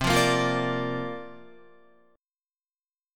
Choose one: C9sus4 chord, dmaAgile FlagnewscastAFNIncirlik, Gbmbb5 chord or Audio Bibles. C9sus4 chord